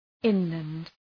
Shkrimi fonetik {‘ınlənd}